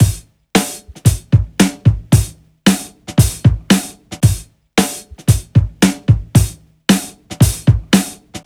• 114 Bpm Drum Loop Sample G Key.wav
Free breakbeat sample - kick tuned to the G note. Loudest frequency: 1126Hz
114-bpm-drum-loop-sample-g-key-qg9.wav